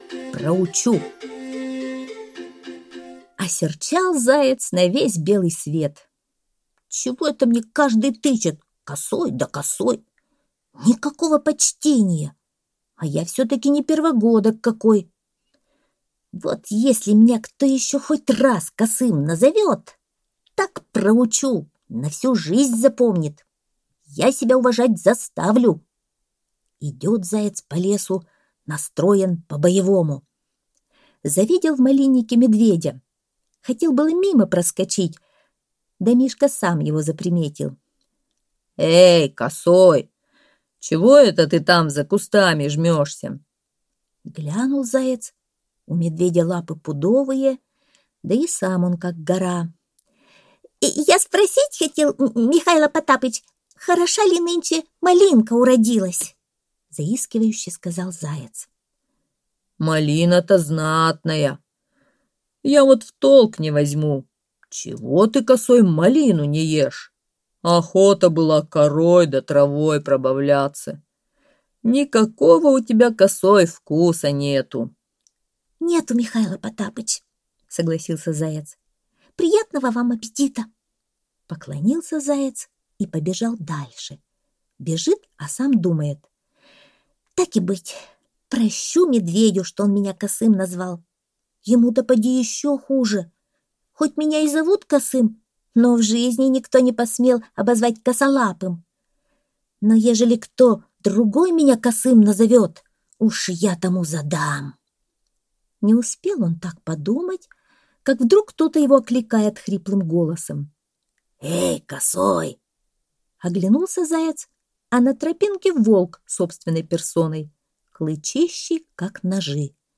Сказки Дремучего леса (аудиоверсия)